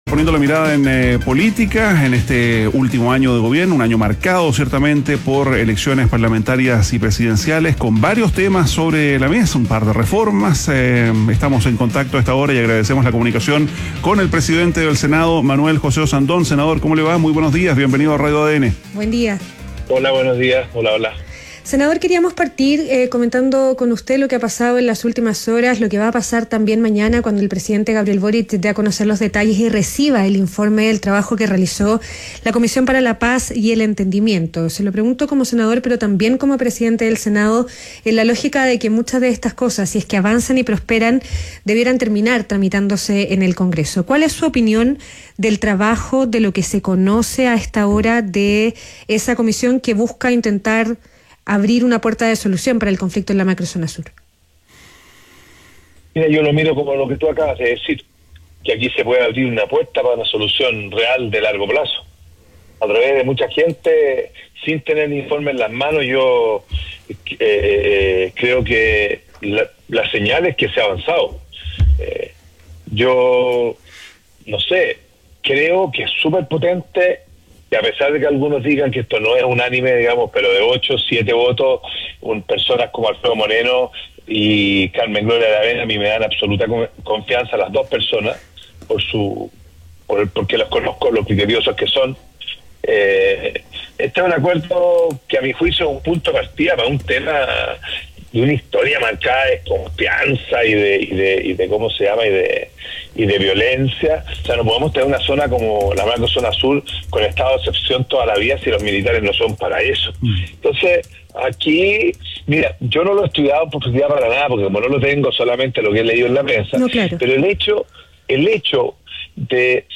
ADN Hoy - Entrevista a Manuel José Ossandón, presidente del Senado